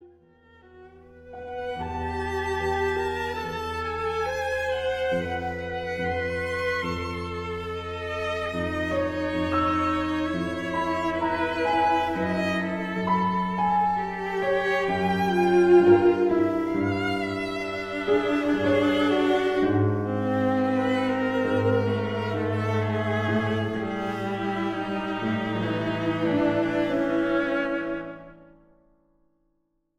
Slow, Calm